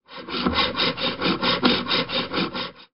Handsaege
Dieses Geräusch ist charakteristisch für eine Handsäge. In diesem Fall handelt es sich um einen so genannten Fuchsschwanz, welcher ein Fichtenbrett zersägt.